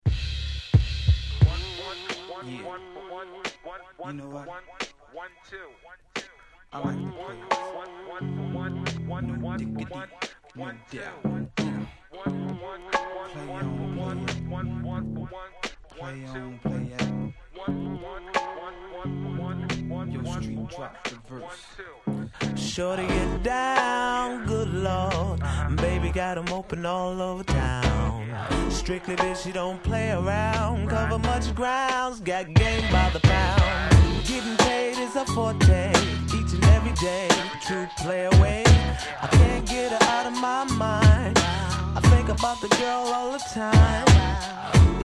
96' Super Hit R&B !!!